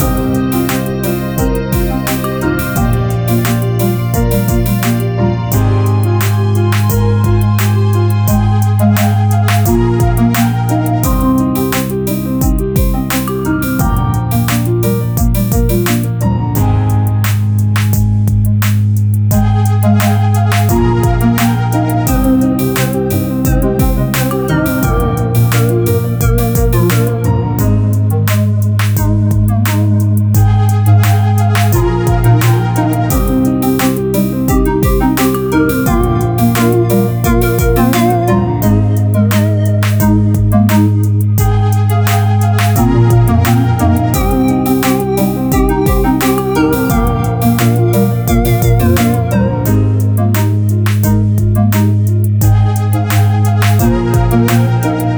An over-the-top triumphant battle jam.